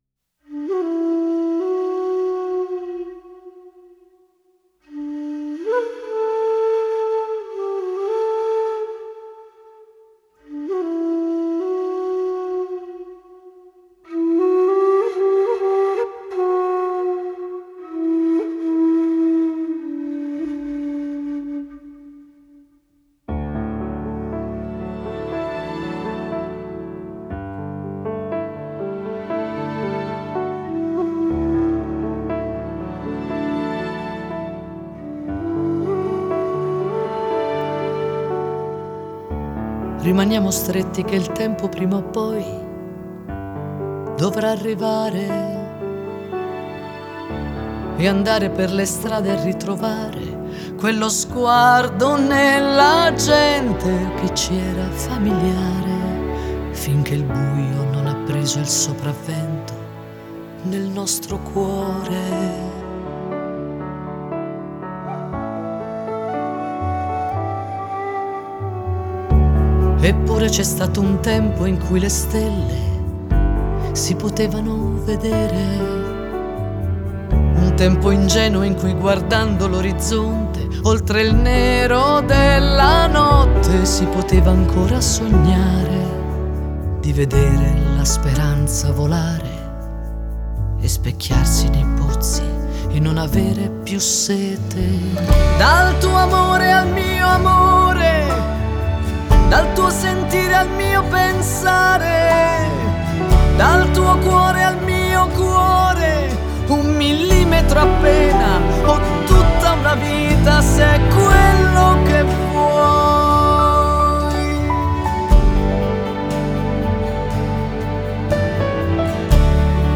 Итальянская эстрада